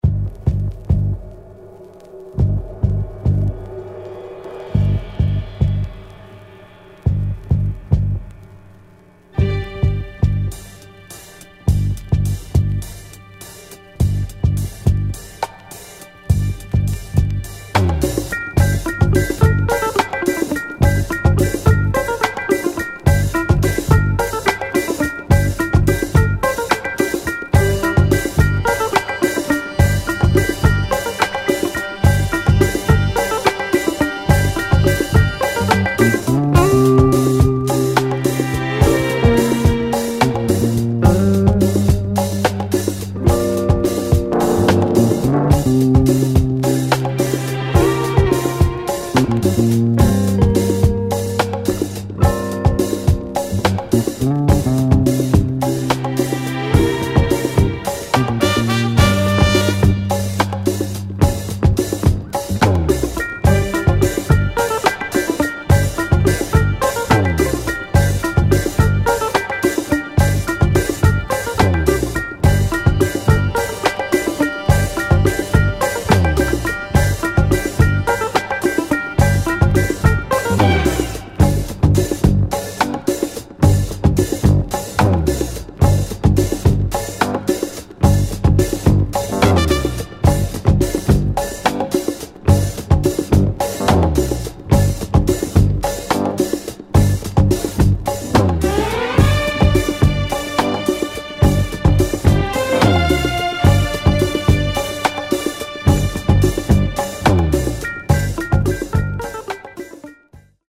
Dope Instrumental Funk